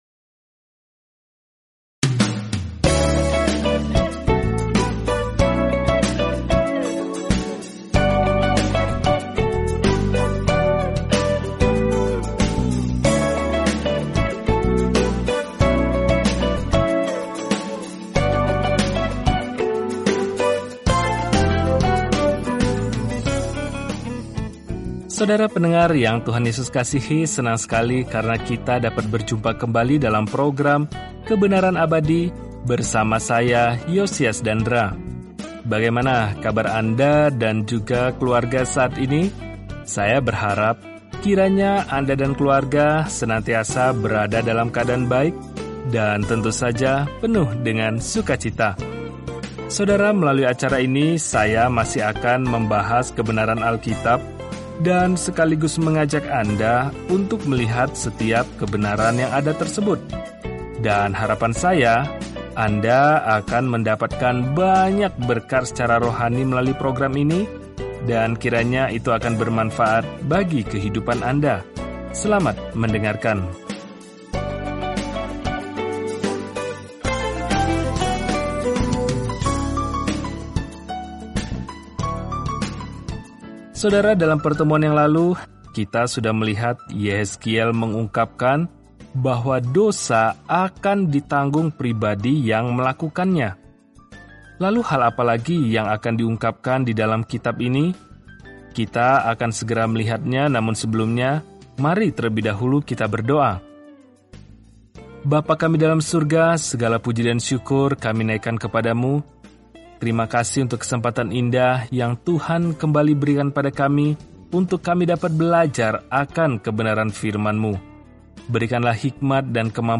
Firman Tuhan, Alkitab Yehezkiel 19 Yehezkiel 20 Hari 11 Mulai Rencana ini Hari 13 Tentang Rencana ini Orang-orang tidak mau mendengarkan peringatan Yehezkiel untuk kembali kepada Tuhan, jadi dia malah memerankan perumpamaan apokaliptik, dan itu menusuk hati orang-orang. Jelajahi Yehezkiel setiap hari sambil mendengarkan pelajaran audio dan membaca ayat-ayat tertentu dari firman Tuhan.